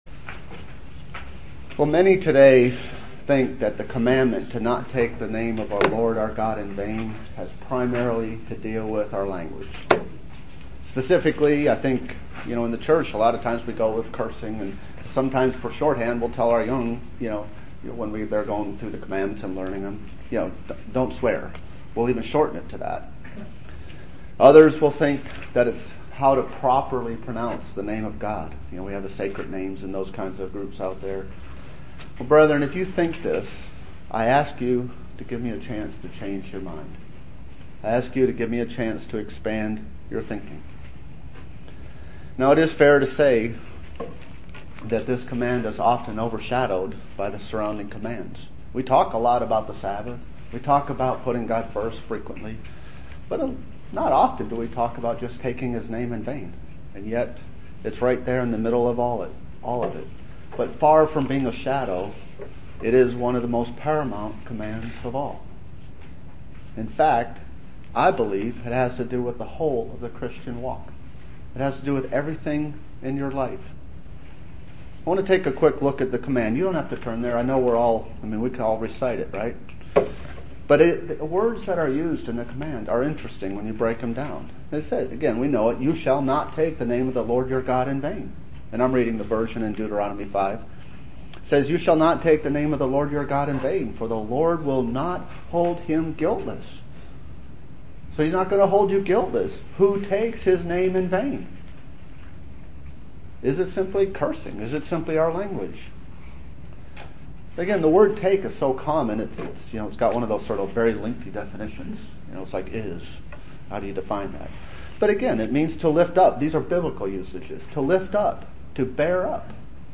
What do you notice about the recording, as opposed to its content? Given in Bowling Green, KY